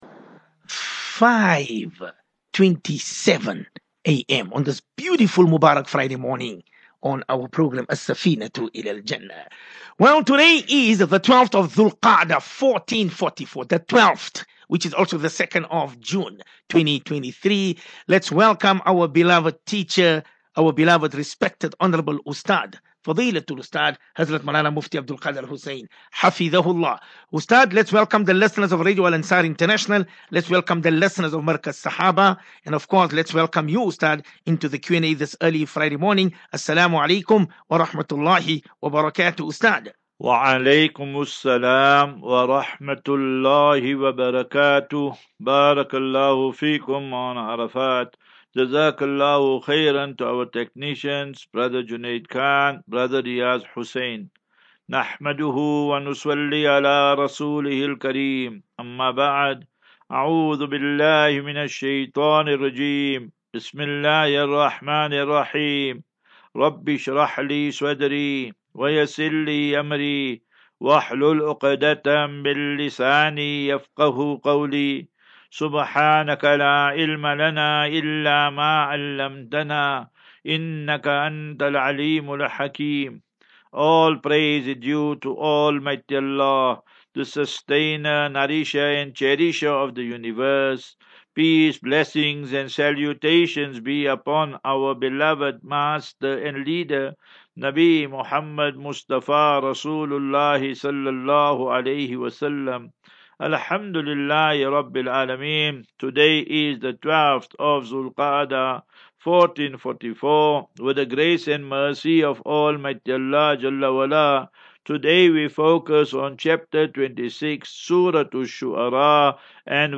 As Safinatu Ilal Jannah Naseeha and Q and A 2 Jun 02 June 23 Assafinatu